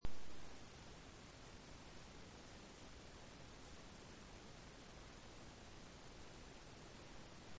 Ambient sound effects
Descargar EFECTO DE SONIDO DE AMBIENTE SILENCE BEAUTIFUL SILENC - Tono móvil